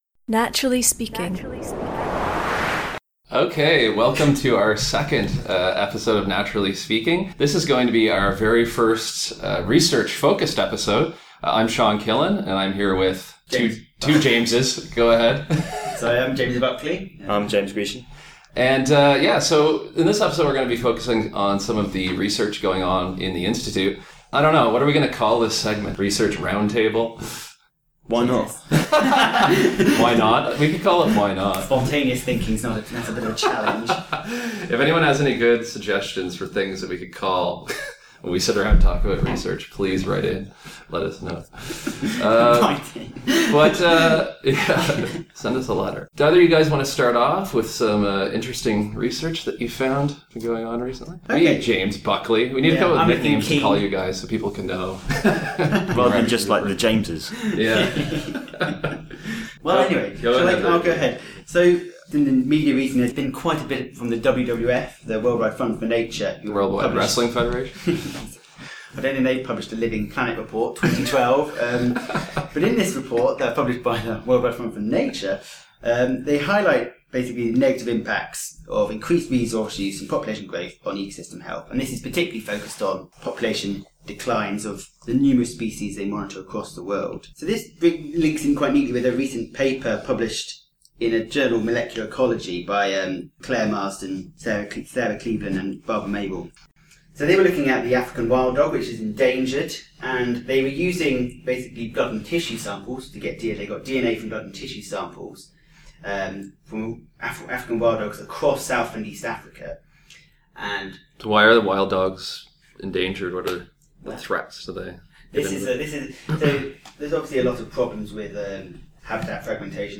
This episode is the first of what we’re calling “Research Round Tables”, in which we’ll be summarizing some recent work from the Institute.